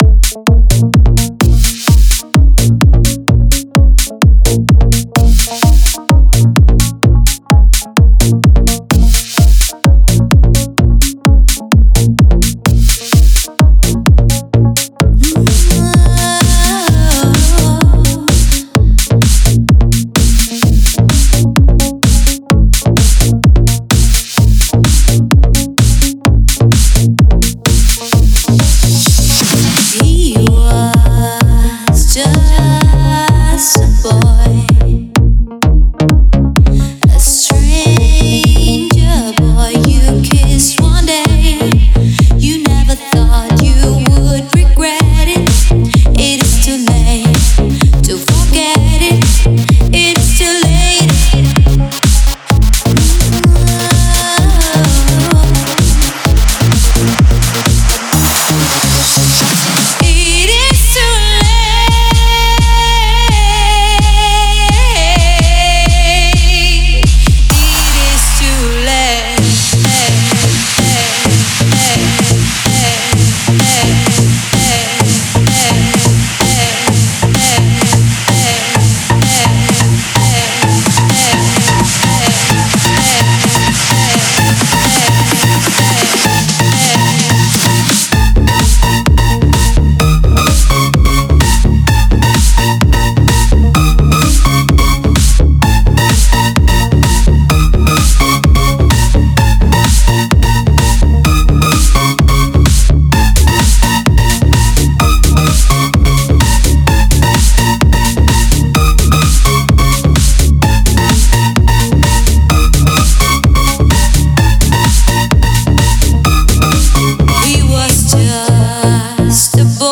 Genre: House.